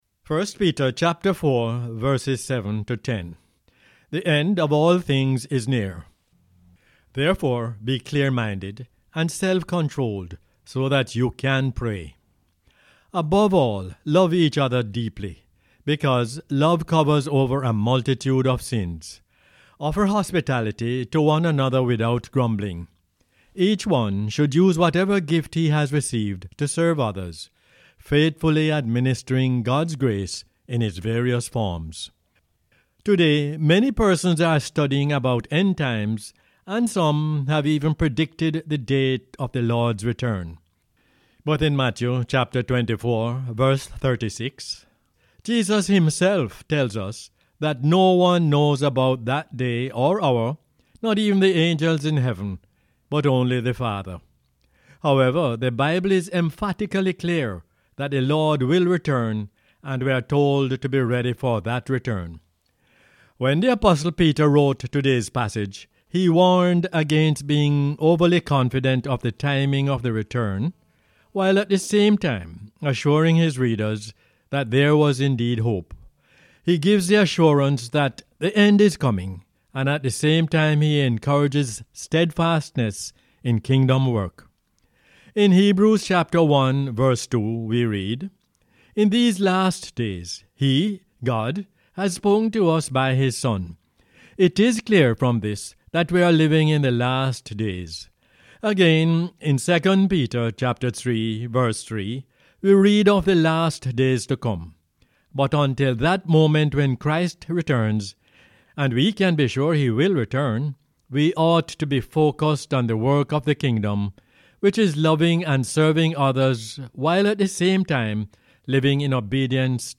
1 Peter 4:7-10 is the "Word For Jamaica" as aired on the radio on 20 September 2019.